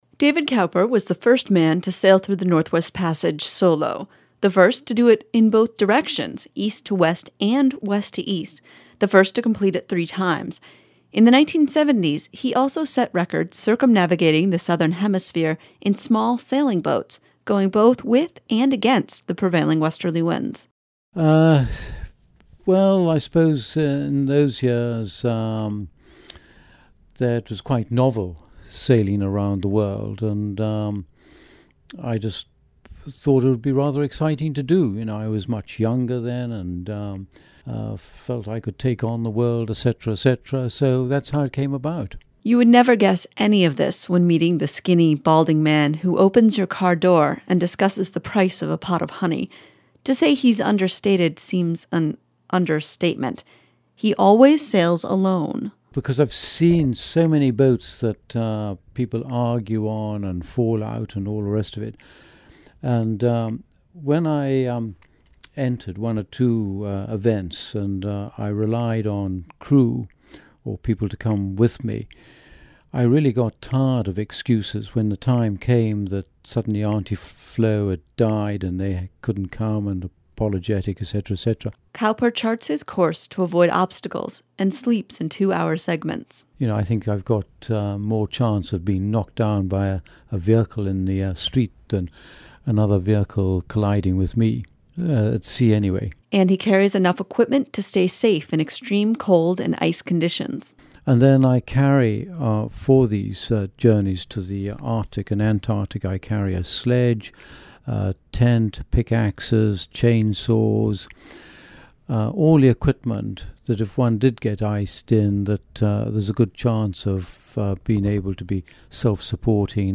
Unalaska, AK